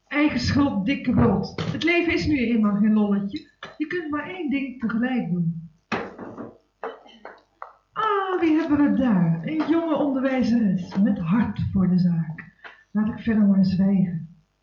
Opname tijdens een Try Out met een cassetterecorder, matige kwaliteit.
Voice over